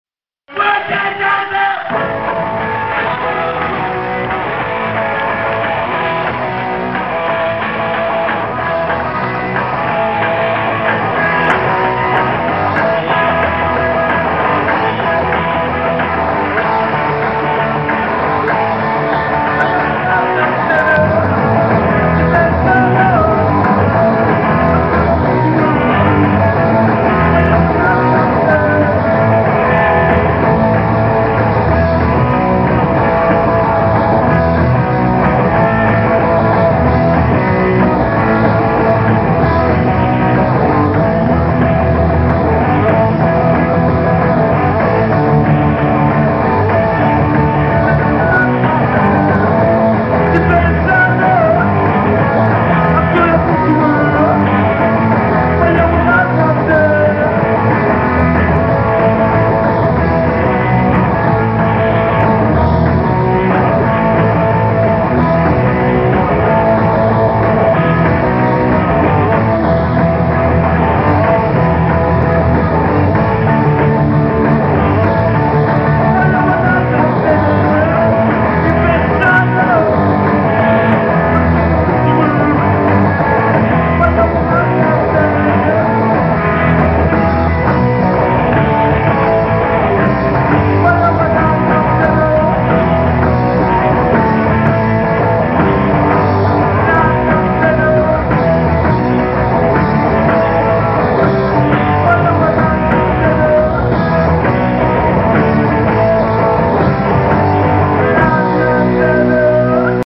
Houston Hall U of P Philadelphia 4-09-77